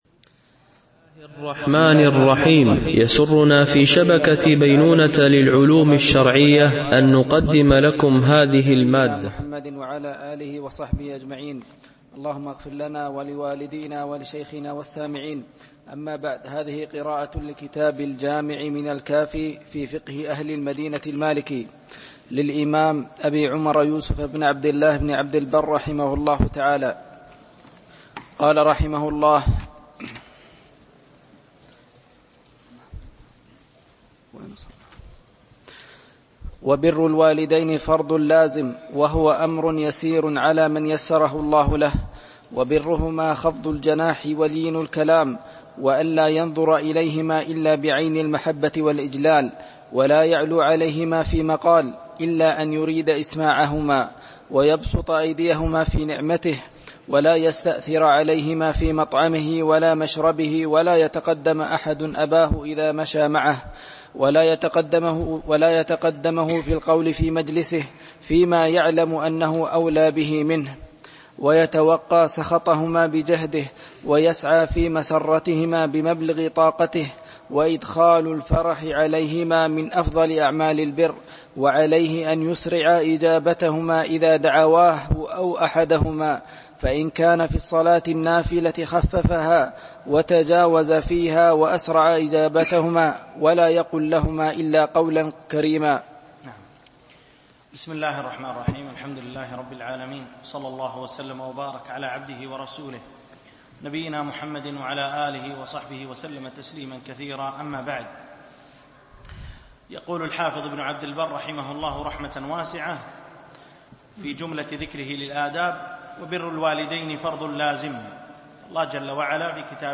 دورة علمية شرعية لمجموعة من المشايخ الفضلاء بمسجد أم المؤمنين عائشة - دبي (القوز 4)